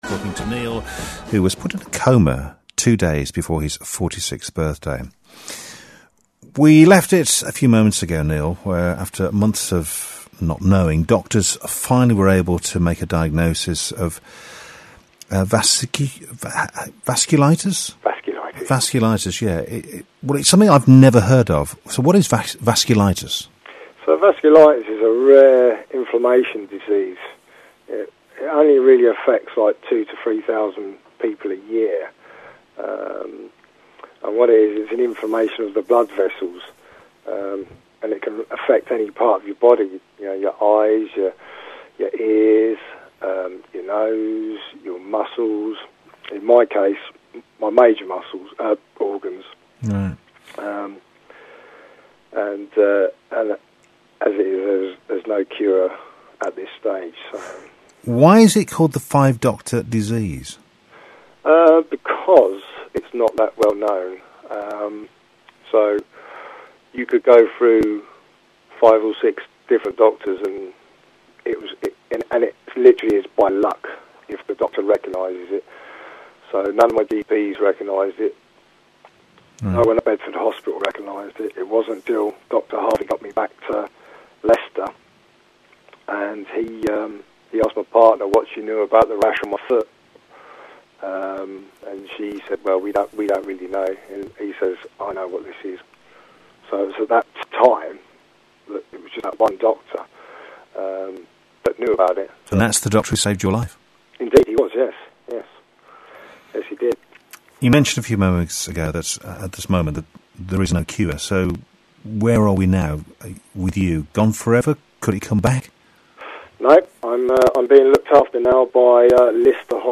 VasculitisInterview.mp3